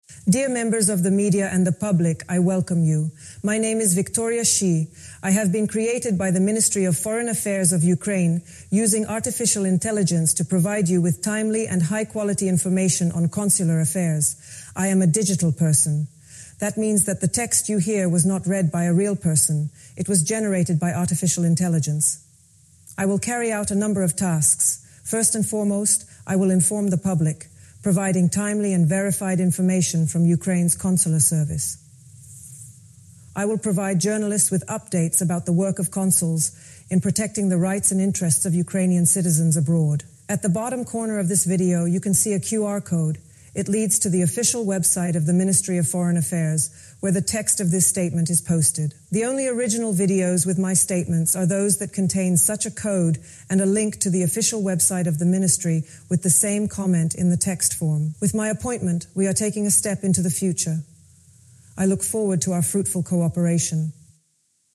Inaugural Remarks Announcing Identity and Purpose Under Ukraine's Ministry of Foreign Affairs/Consular Affairs
Audio mp3 of Address in English       Audio AR-XE mp3 of Address in English